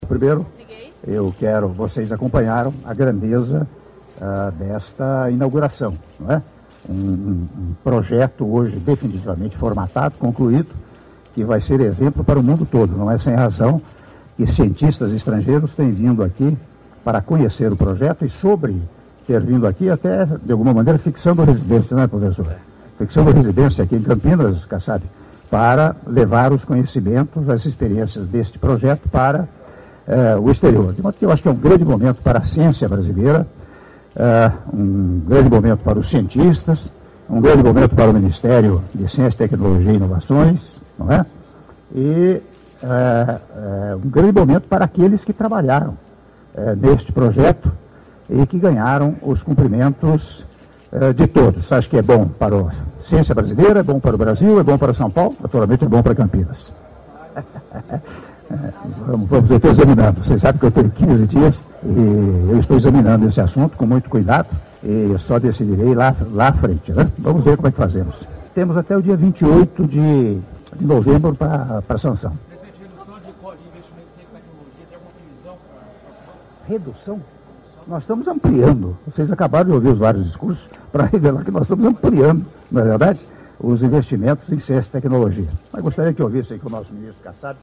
Áudio da entrevista do presidente da República, Michel Temer, após cerimônia de Inauguração da 1ª etapa do Projeto Sirius- Campinas/SP-(01min27s)